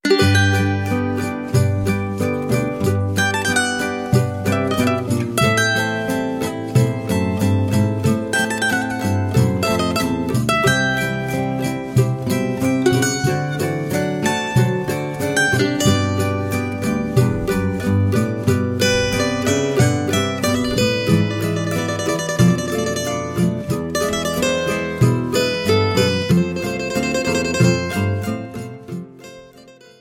cavaquinho
Choro ensemble